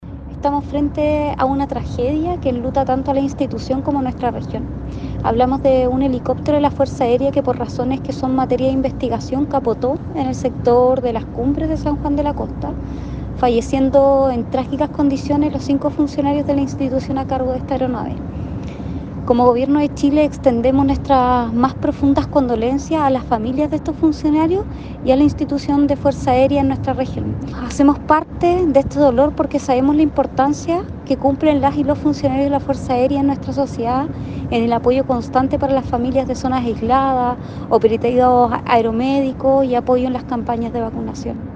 La delegada presidencial regional Giovanna Moreira entregó condolencias a la Fuerza Aérea de Chile por el deceso de cinco de sus integrantes.